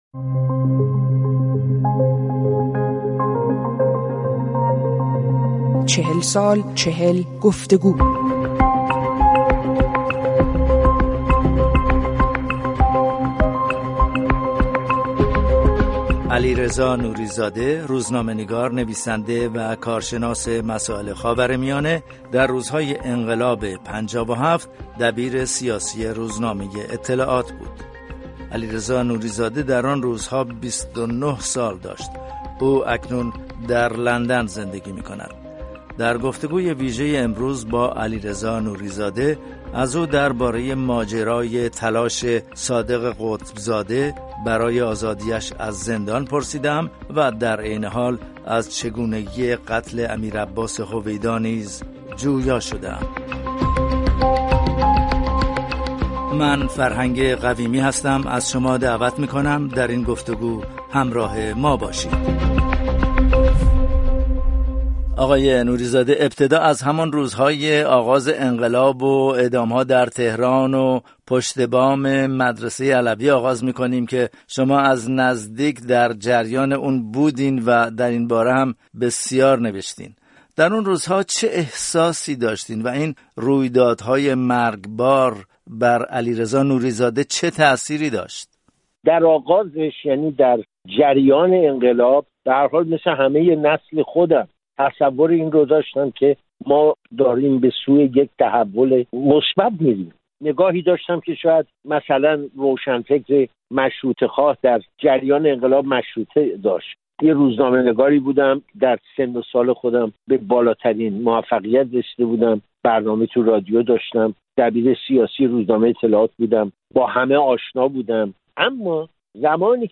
در این گفت‌وگوی ویژه از او درباره ماجرای تلاش صادق قطب‌زاده برای آزادی‌اش از زندان پرسیده‌ایم و نیز چگونگی قتل امیرعباس هویدا را جویا شده‌ایم.